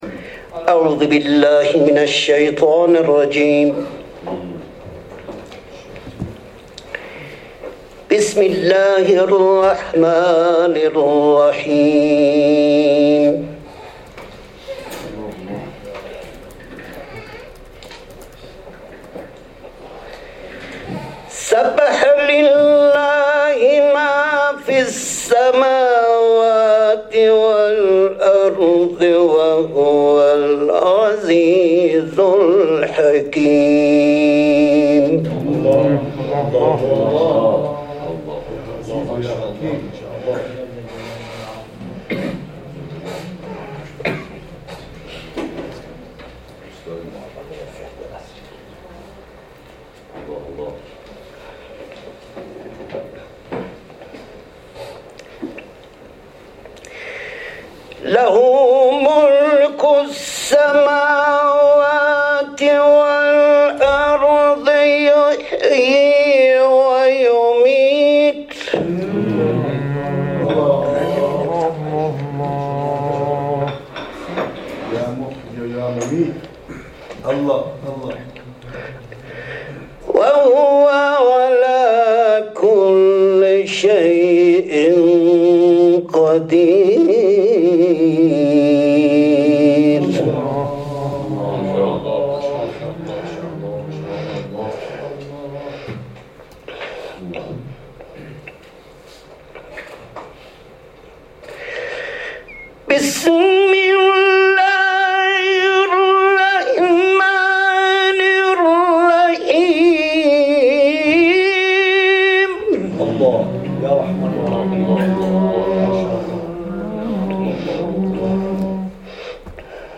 گروه فعالیت‌های قرآنی: جدیدترین تلاوت قاری بین‌المللی و مدرس قرآن کشورمان از آیات ابتدایی سوره حدید و سوره ضحی ارائه می‌شود.